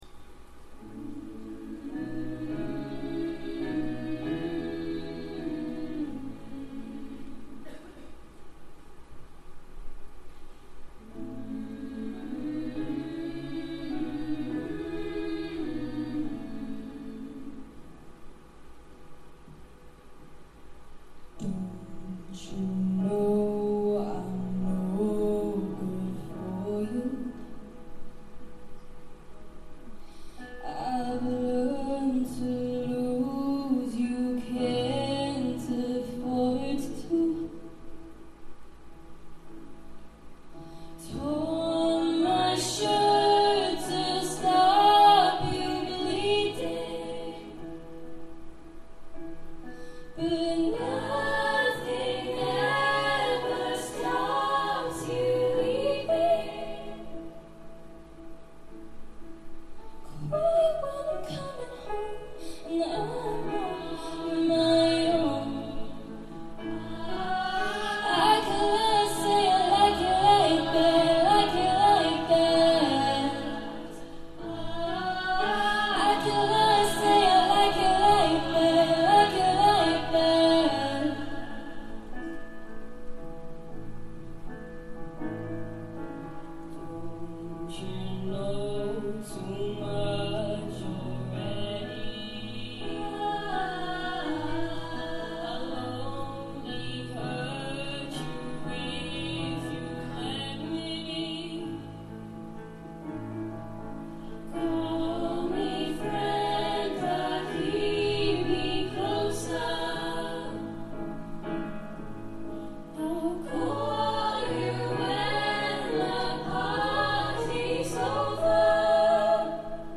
Senior Choir